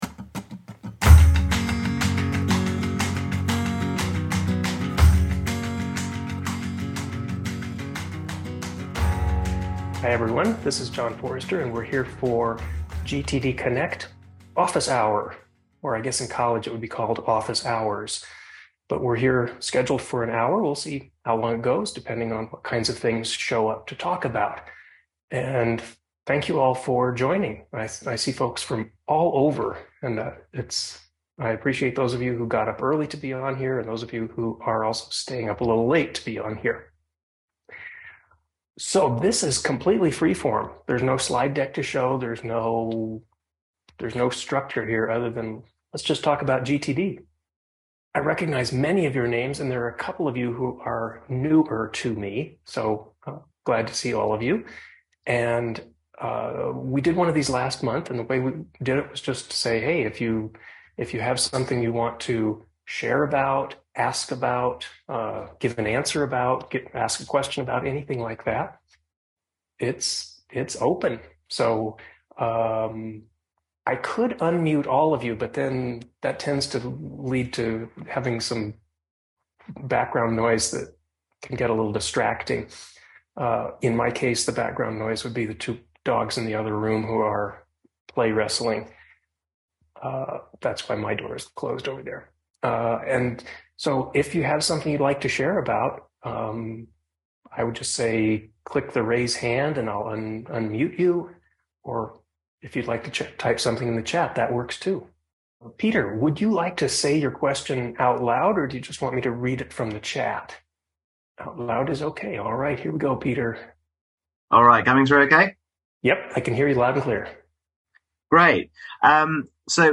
You will hear interviews with people from all walks of life about their journey with GTD, from beginners to those who have been at it for years. The podcasts include personal and professional stories, as well as practical tips about GTD systems for desktop and mobile, using apps and paper.